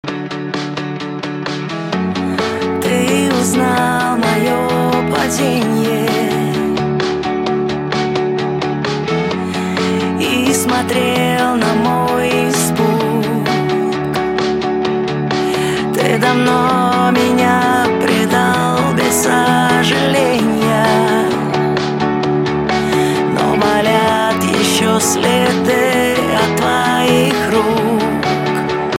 гитара
женский вокал
грустные
спокойные
Pop Rock